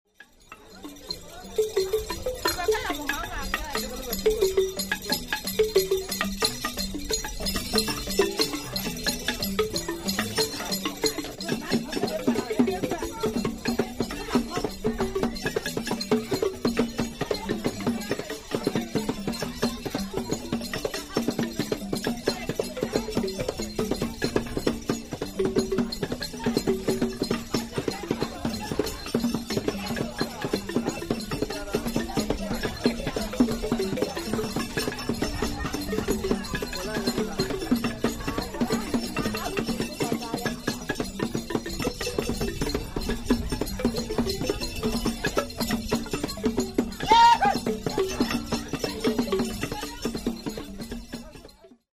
The jengsing is a xylophone with gourd resonators.
The jengsing is played with two wooden sticks, the heads of which are made of rubber. It is traditionally played in pairs in combination with the pendere drum at funerals.